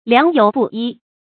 良莠不一 liáng yǒu bù yī
良莠不一发音